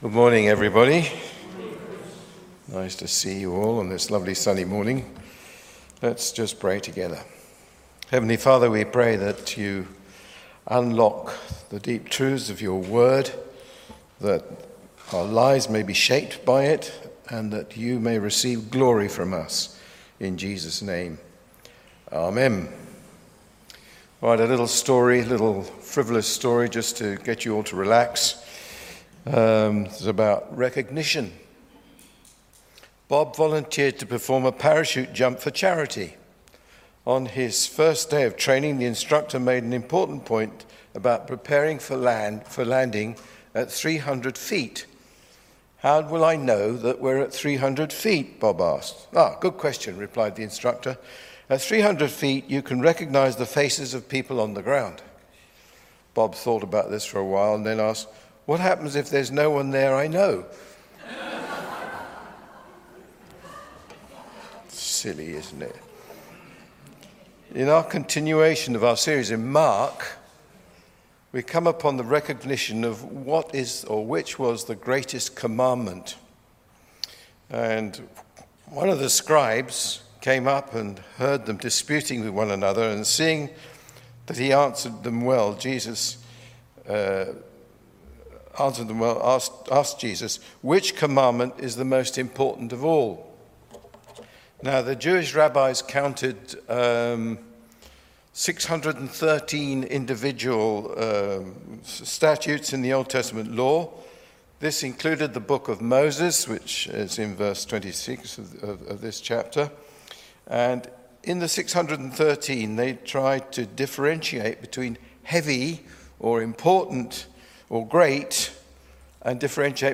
Sermon on Mark 12:28-44